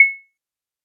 MANNIE_FRESH_clave_one_shot_long.wav